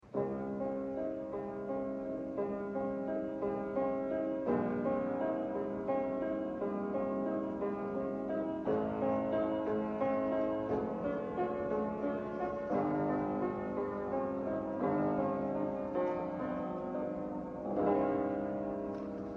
Fortepiano
They call it a forte piano because it can be both loud and a piano.
Fortepiano.mp3